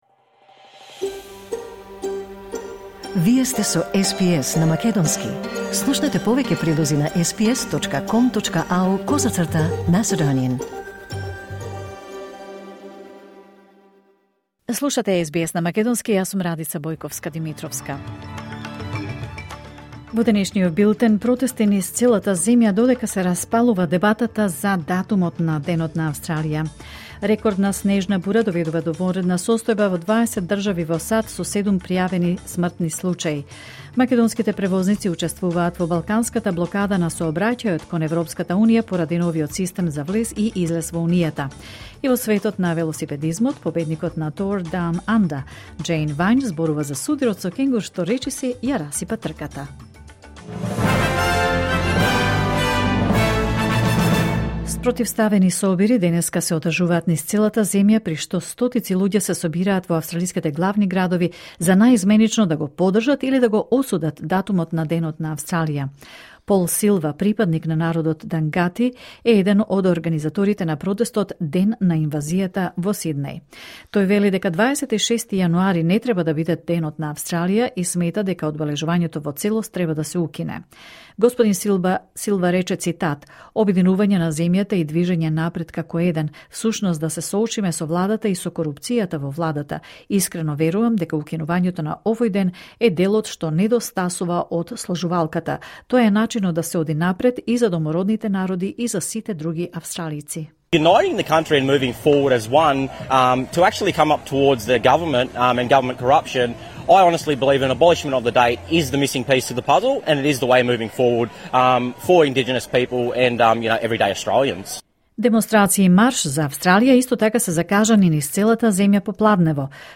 Вести на СБС на македонски 26 јануари 2026